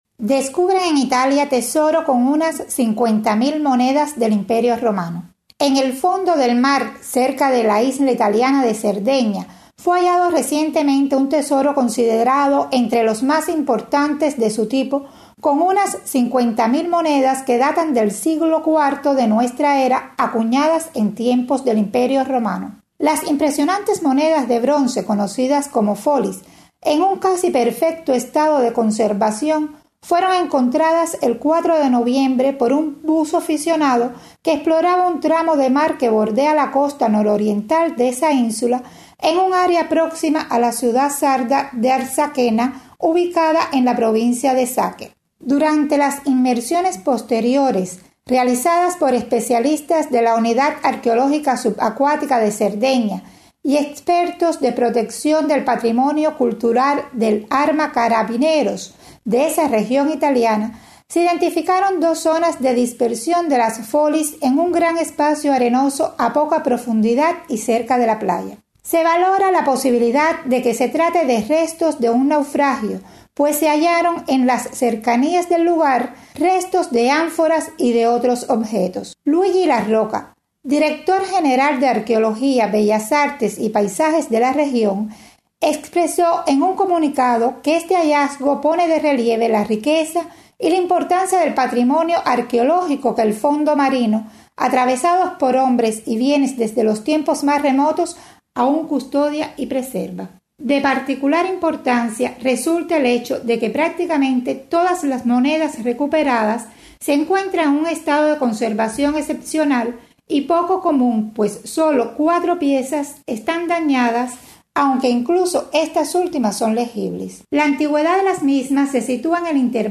desde Roma